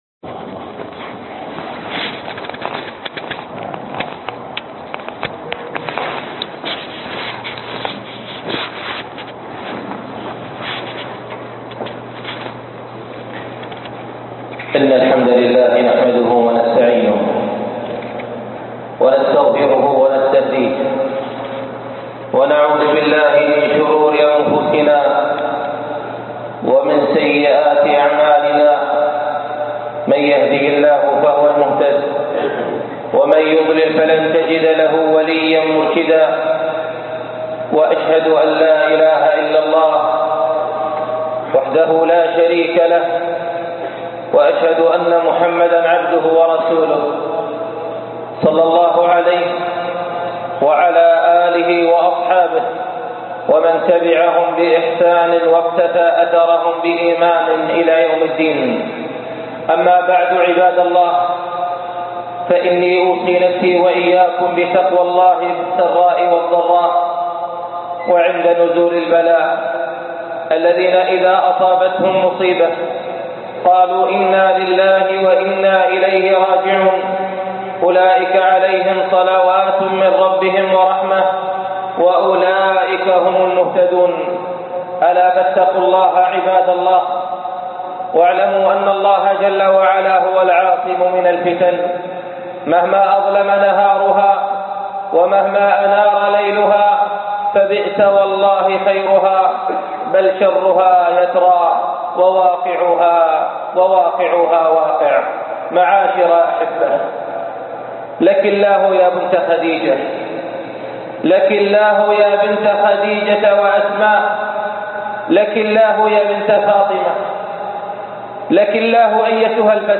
معركة المرأة (1434 هـ ) خطبة الجمعة